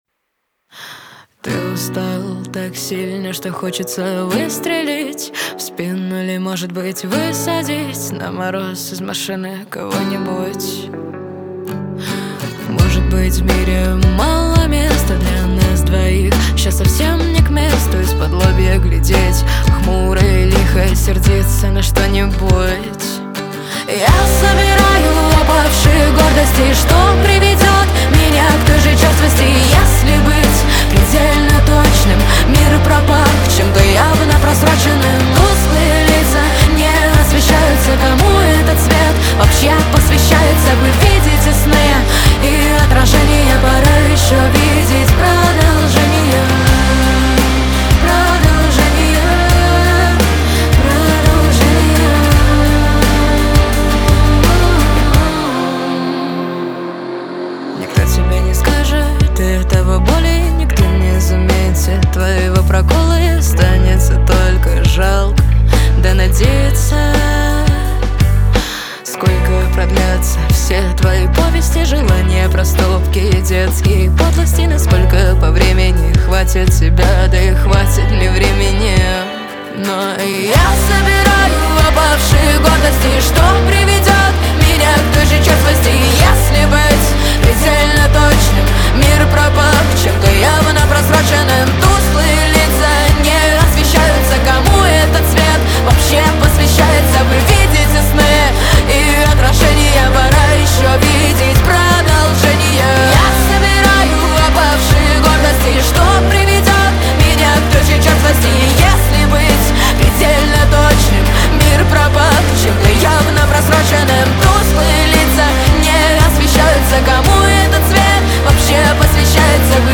Рок музыка 2025